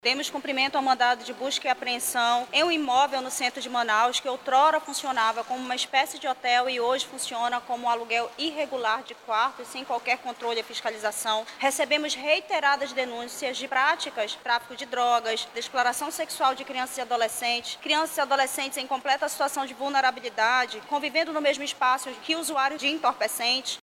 A delegada Juliana Tuma, titular da Delegacia Especializada em Proteção à Criança e ao Adolescente – Depca, ressalta que o local funcionava de forma irregular para atividades criminosas envolvendo crianças e adolescentes.